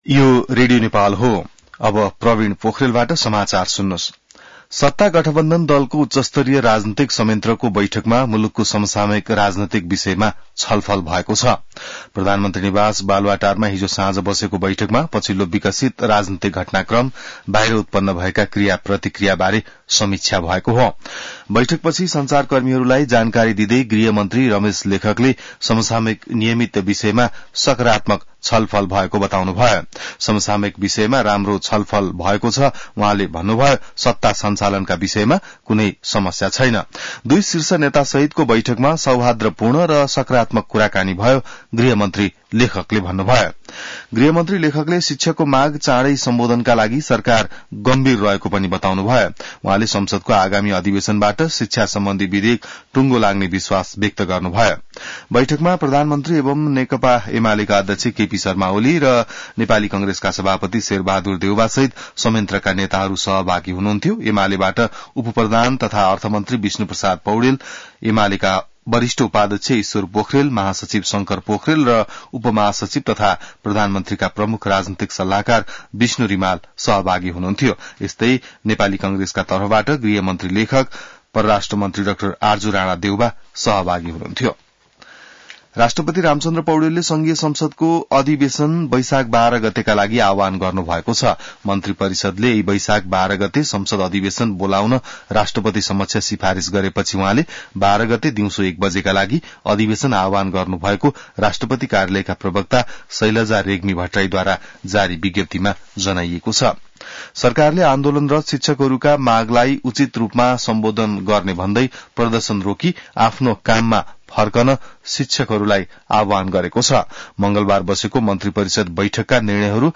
बिहान ६ बजेको नेपाली समाचार : ४ वैशाख , २०८२